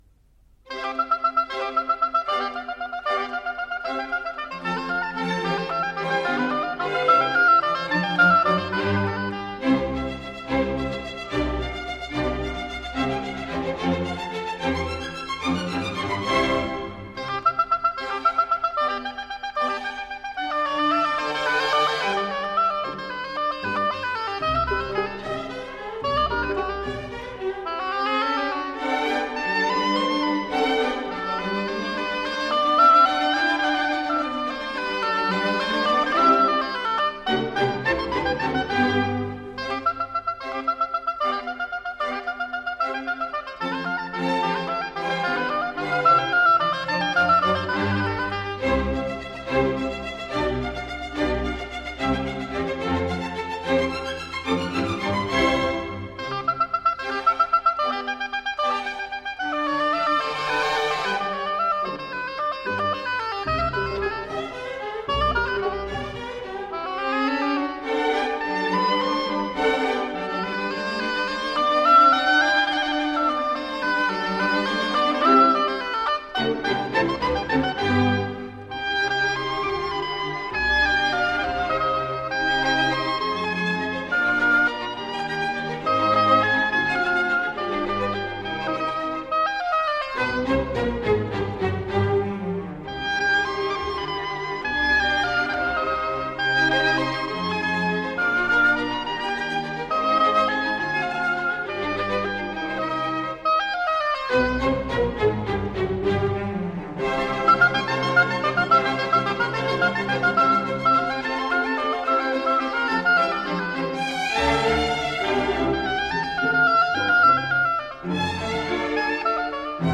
音乐类型：古典音乐 / 管弦乐